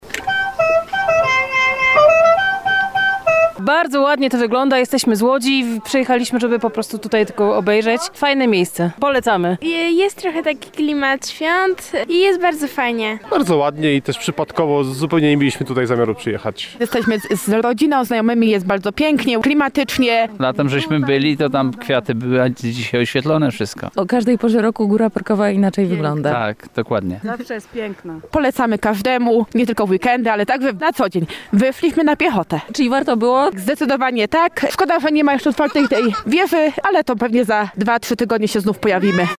5sonda_park_swiatel.mp3